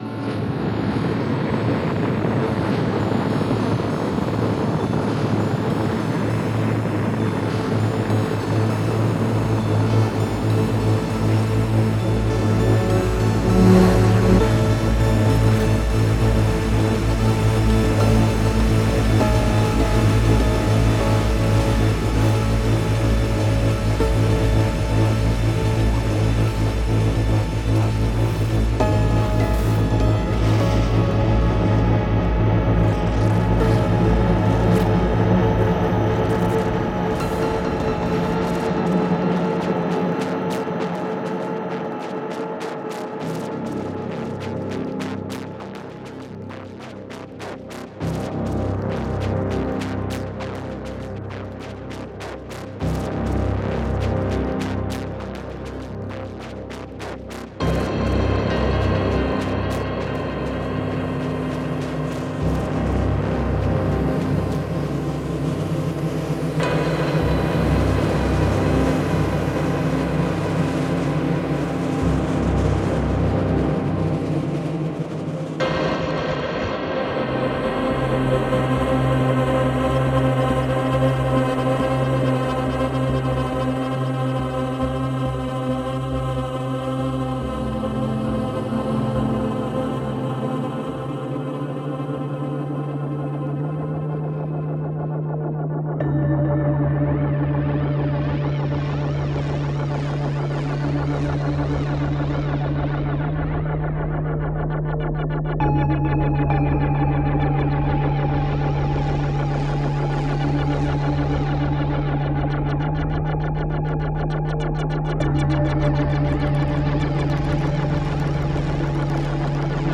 Genre:Ambient
このコレクションはサウンドデザインの暗い側面に焦点を当て、神秘や不安を呼び起こす厳選されたオーディオ素材を提供します。
これらのサウンドは単なる背景要素ではなく、リスナーを冷たく不気味な風景に引き込む没入型の音響環境として設計されています。
45 Atmos Loops
14 Bass Loops
12 piano Loops
26 Synth Loops